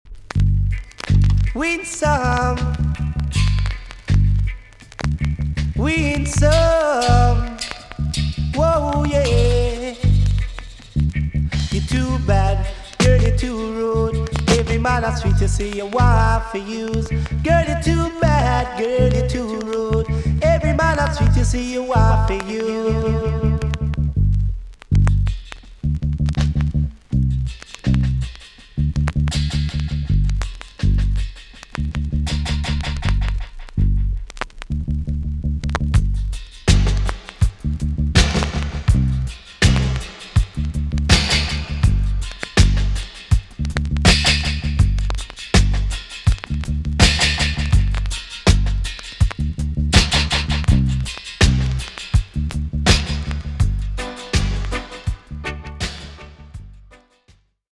TOP >80'S 90'S DANCEHALL
B.SIDE Version
VG+ 少し軽いチリノイズがあります。